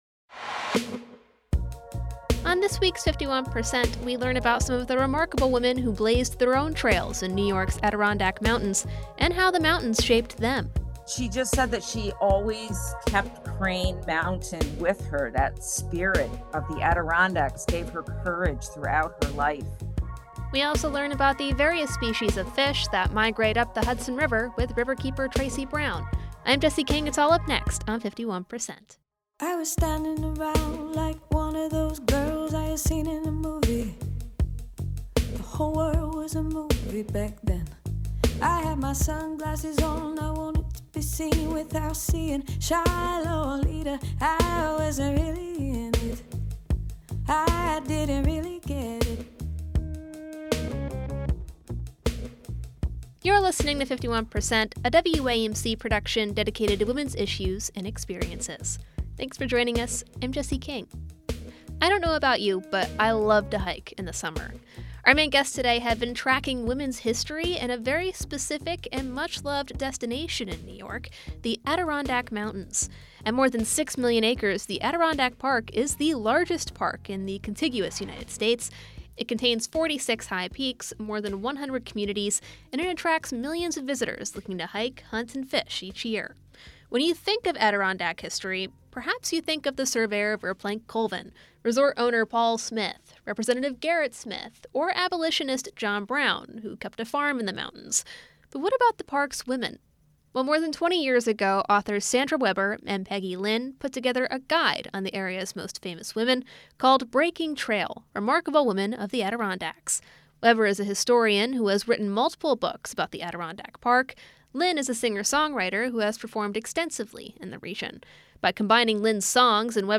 On this week's 51%, we speak with the authors of Breaking Trail: Remarkable Women of the Adirondacks.